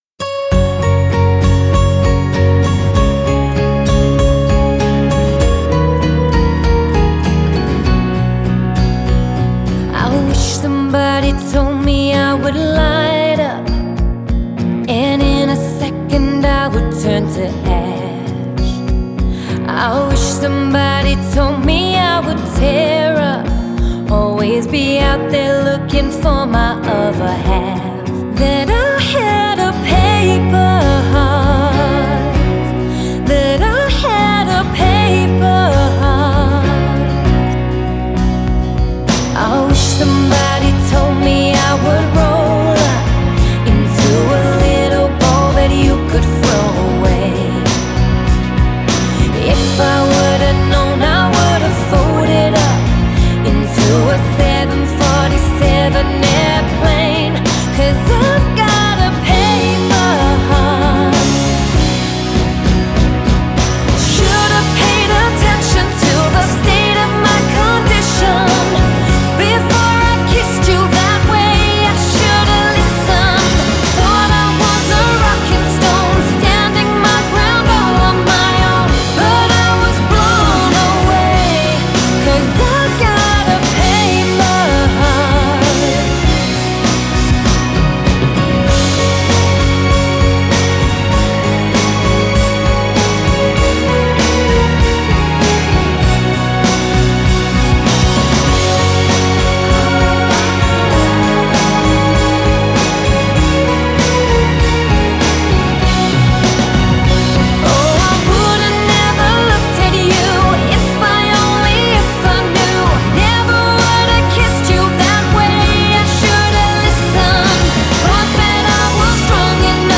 Genre: Country, Folk, Female Vocalist